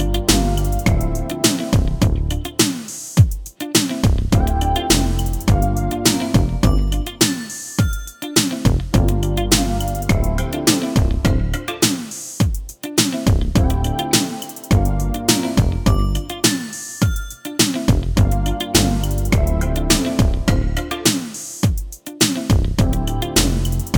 no Backing Vocals Pop (1980s) 3:47 Buy £1.50